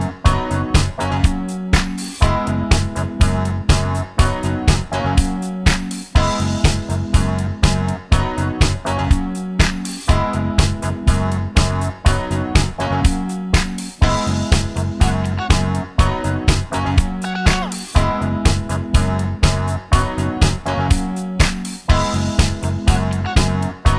Two Semitones Down Disco 3:40 Buy £1.50